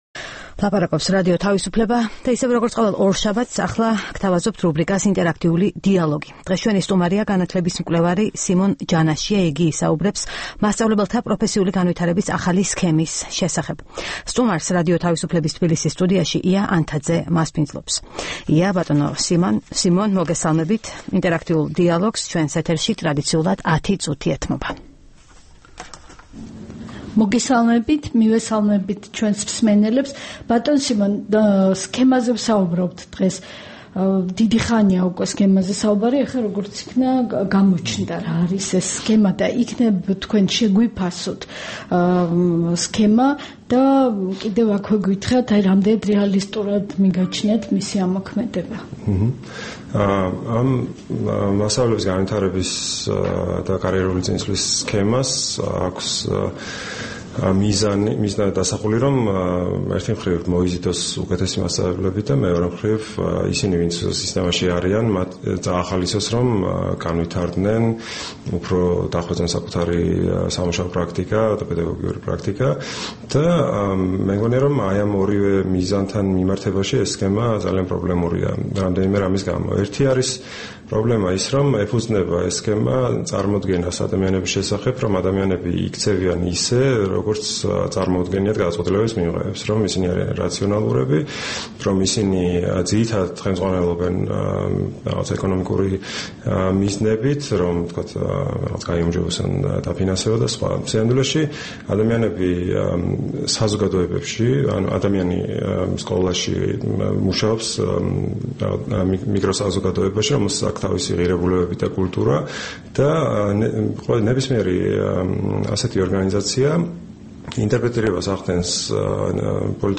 „ინტერაქტიული დიალოგის“ სტუმარი